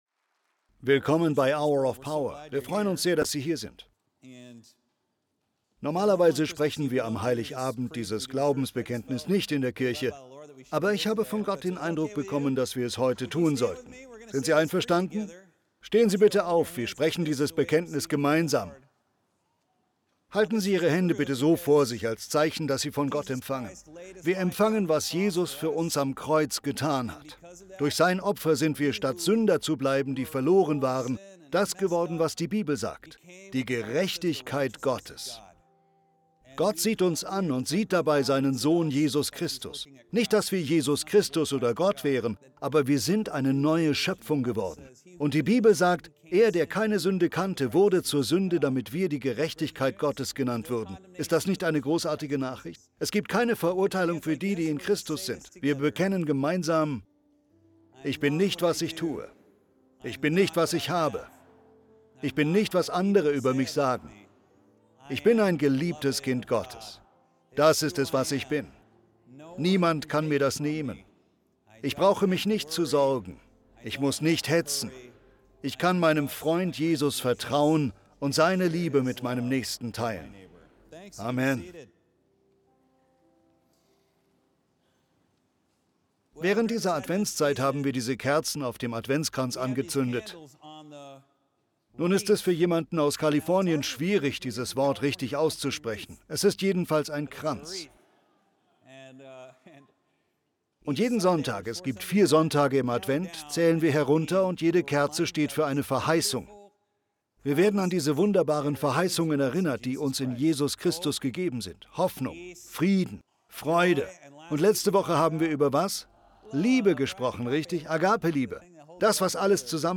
Xmas_2025_Predigt.mp3